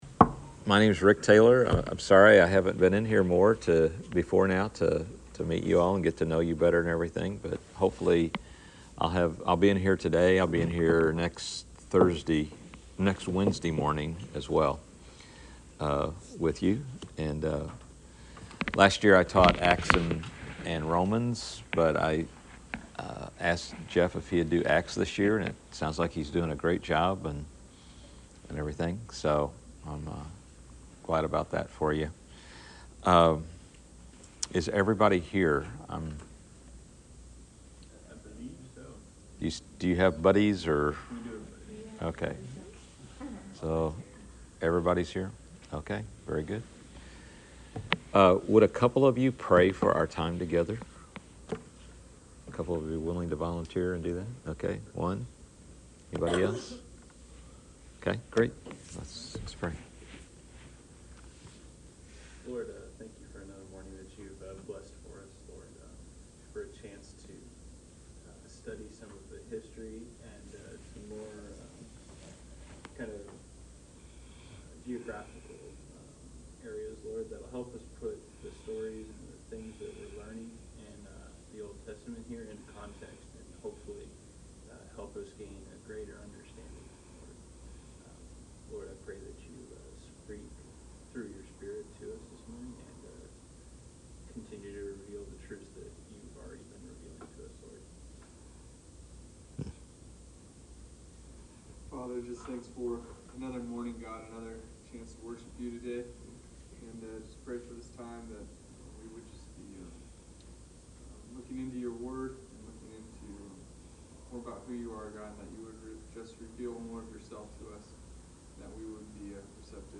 Class Session Audio October 25